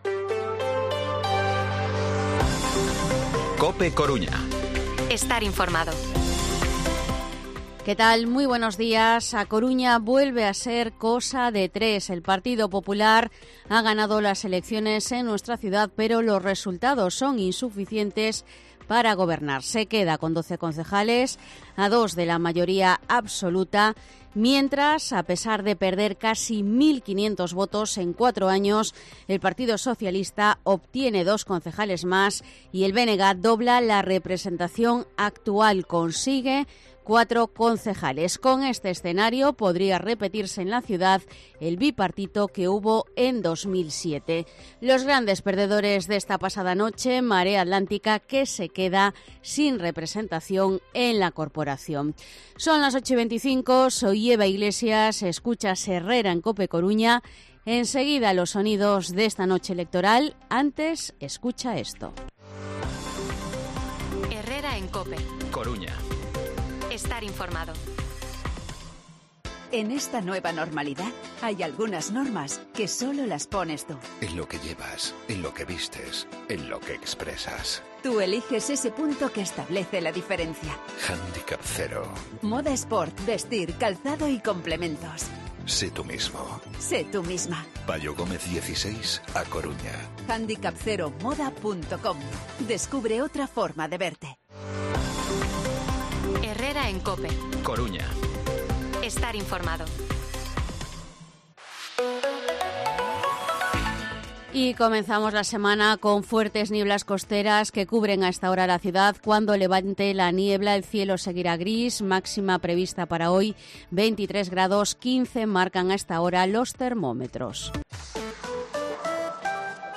Informativo Herrera en COPE lunes, 29 de mayo de 2023. De 8:24 a 8:29 horas